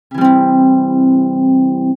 5.2. コード（和音）
ここでは、例としてCメジャーコード（ド、ミ、ソ）を弾いてみましょう。
音程は1弦から順にミ4、ド4、ソ3、ミ3、ド3です。